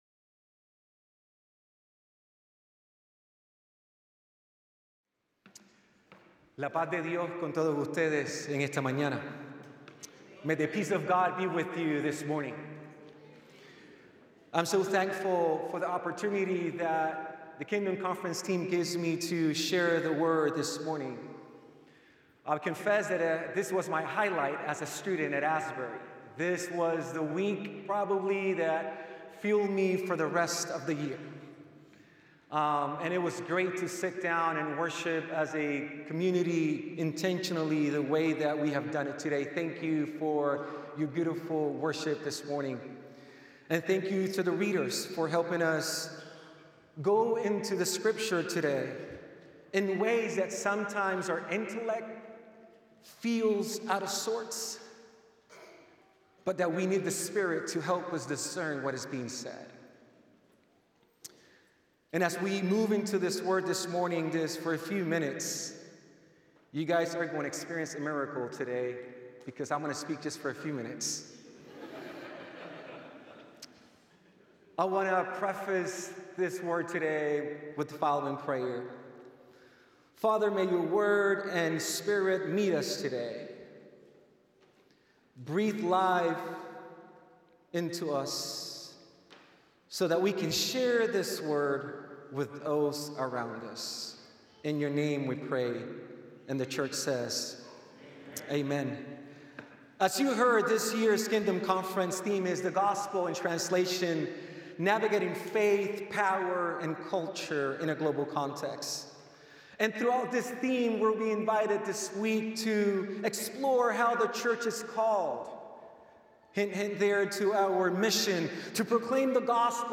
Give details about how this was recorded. The following service took place on Tuesday, October 7, 2025.